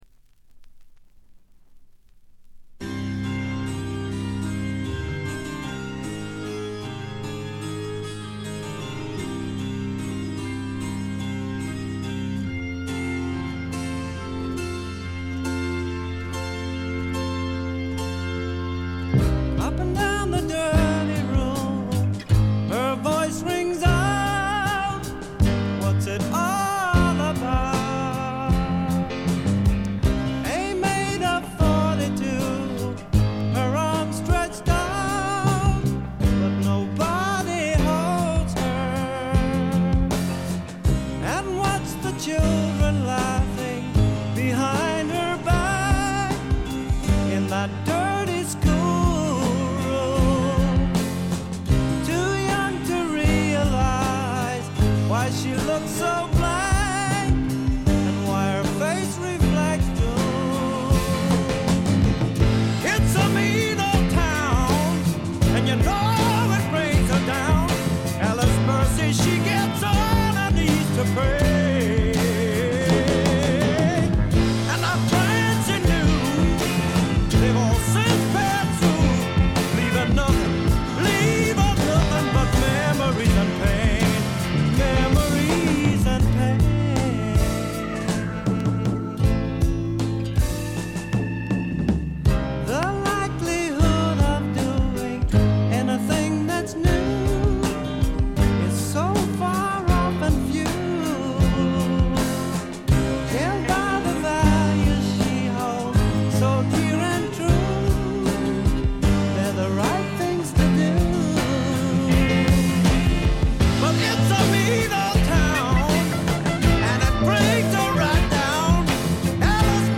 ところどころでチリプチ。散発的なプツ音2-3回。
試聴曲は現品からの取り込み音源です。